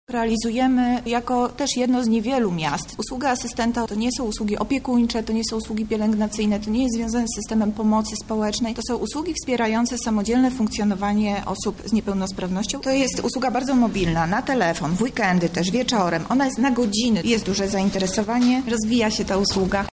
O zadaniach asystenta mówi Monika Lipińska, zastępca prezydenta Lublina do spraw społecznych